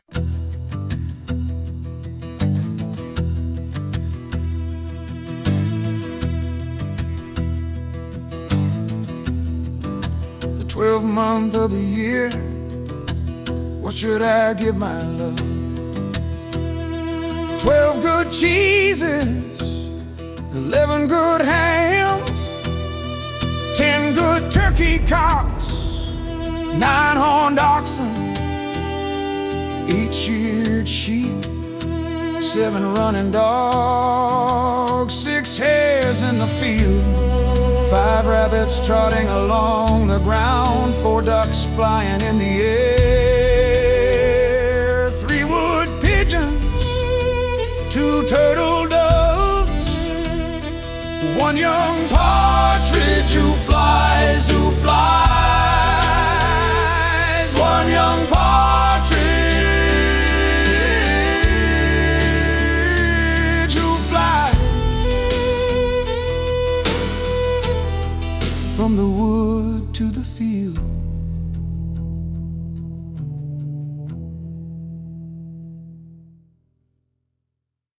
English Christmas Carol, circa 1780, possibly as early as 1500s